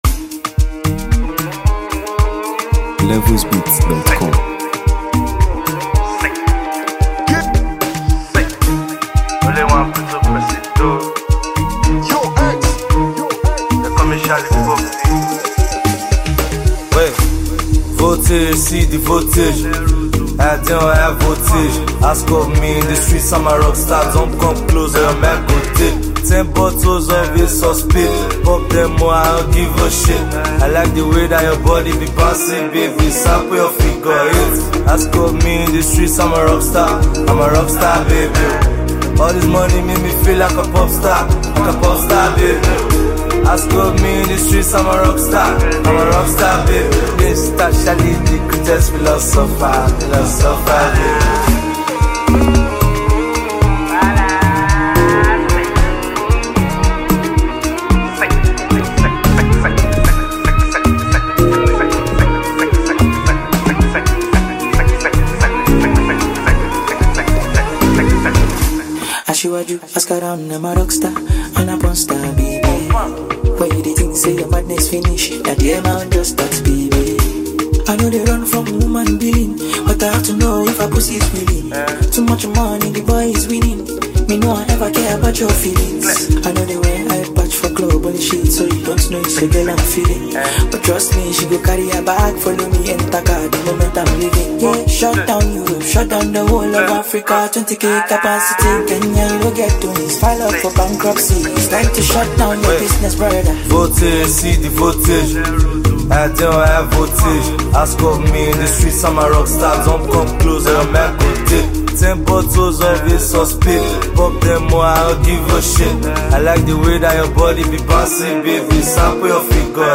a top-tier Nigerian afrobeat singer and wordsmith